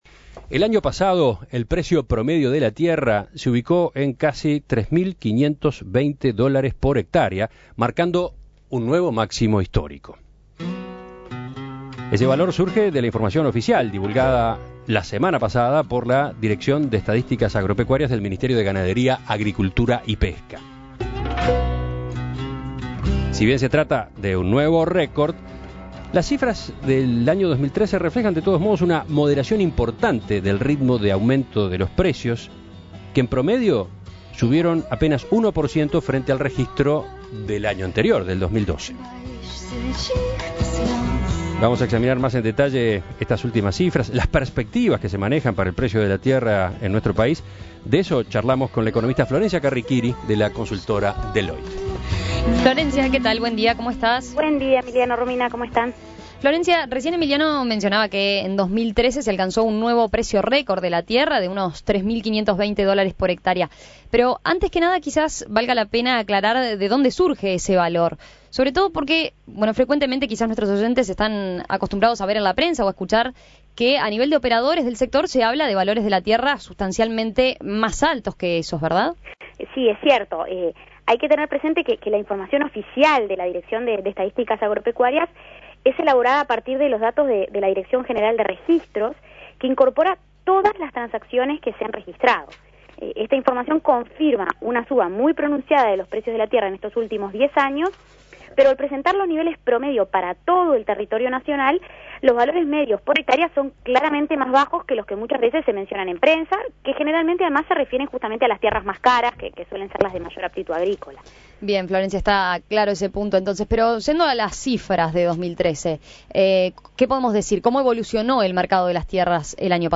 Análisis y perspectivas